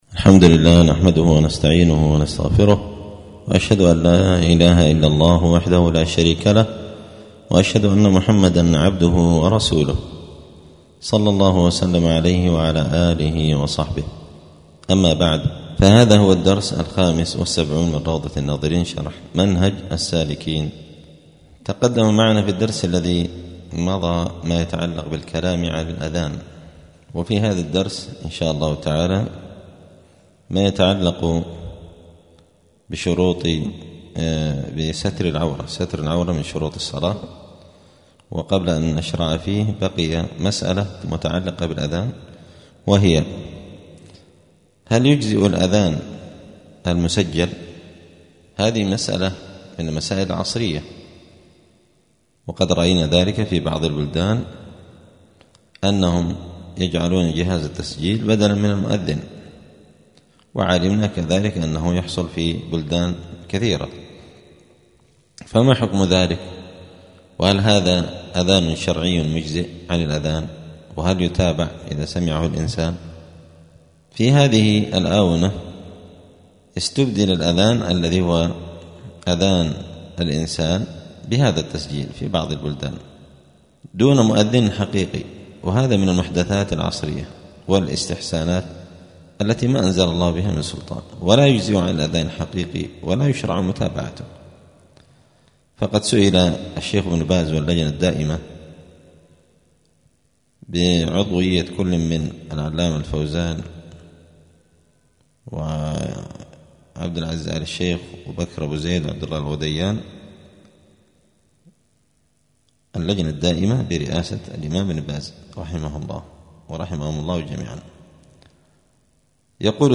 *الدرس الخامس والسبعون (75) {كتاب الصلاة باب شروط الصلاة ستر العورة}*
دار الحديث السلفية بمسجد الفرقان قشن المهرة اليمن